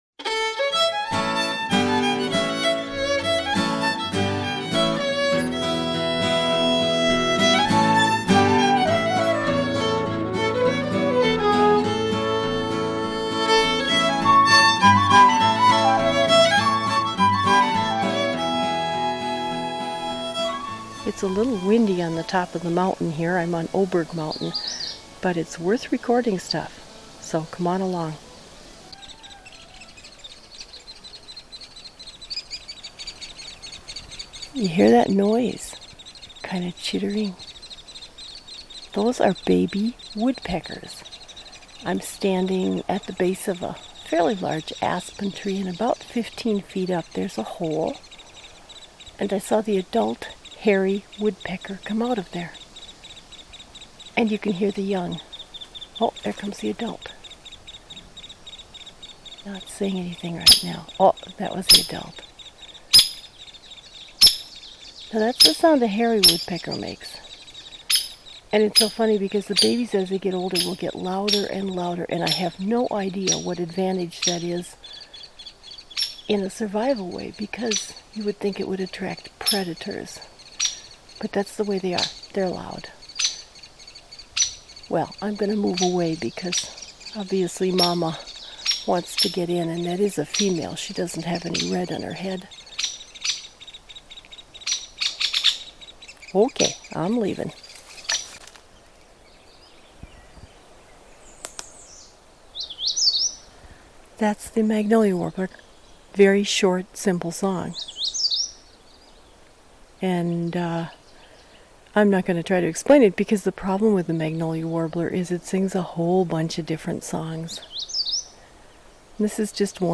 Field Notes: Hairy woodpecker babies